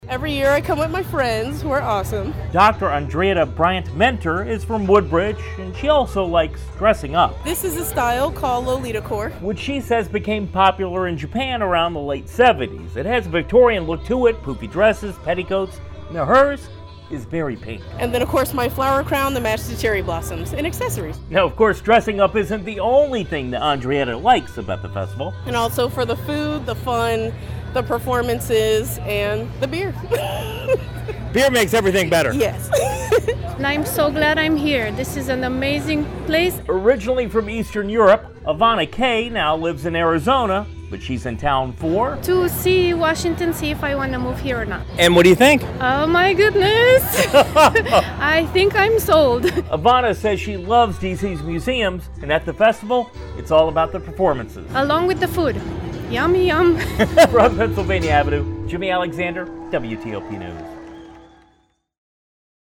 talks with visitors at the Japanese Street Festival in D.C.